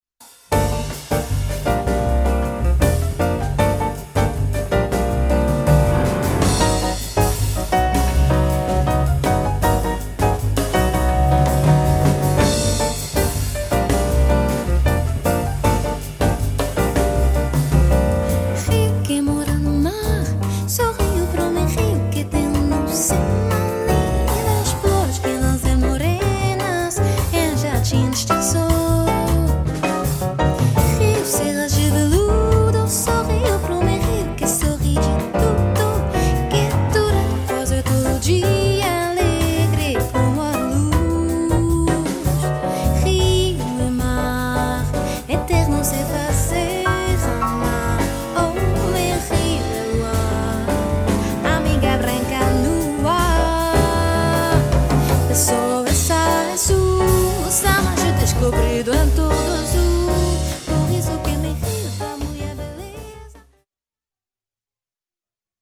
chant
piano
contrebasse
batterie